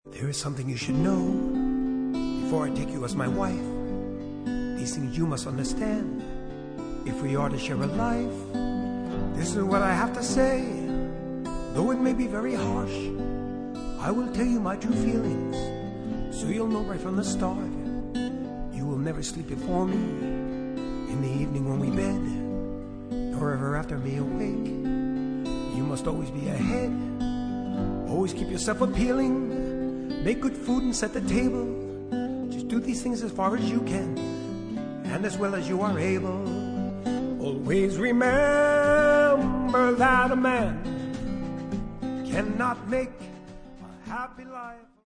トリビュートアルバム